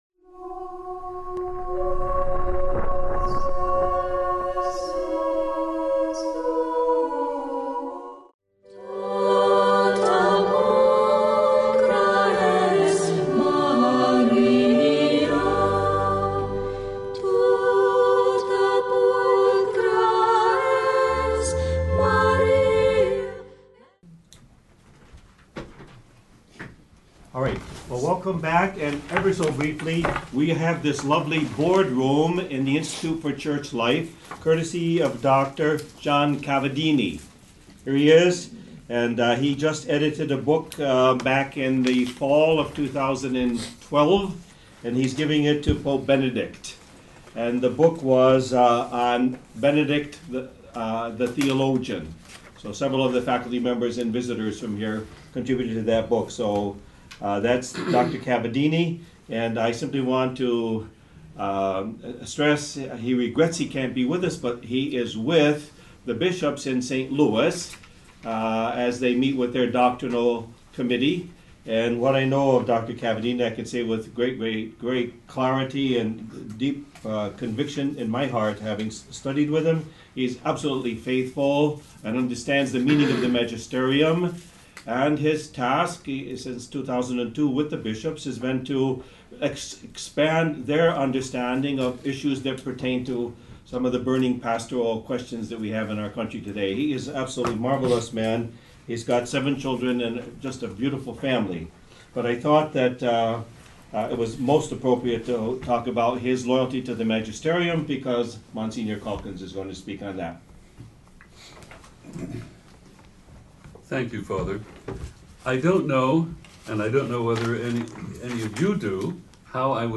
At the Symposium titled "Sursum Actio" at Notre Dame Univ. from Jun 8-9, 2015